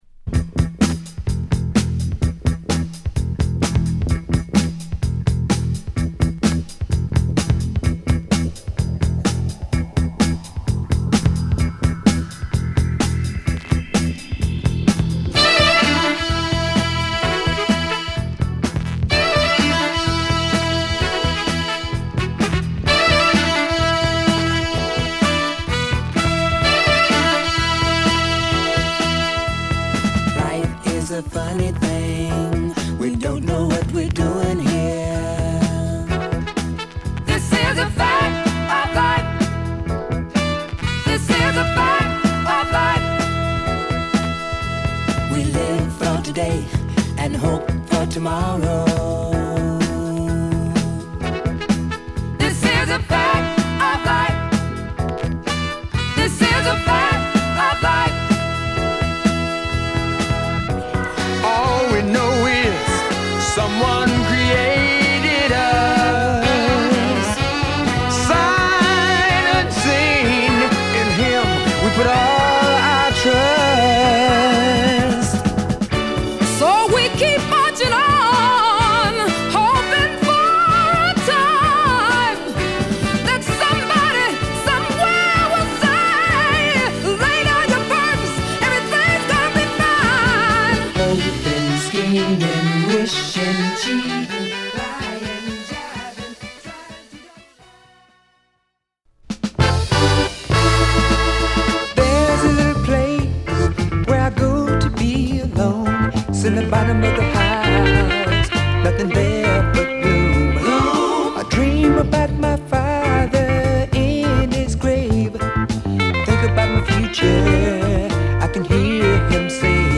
ソウルフルなロックサウンドを展開した1枚です。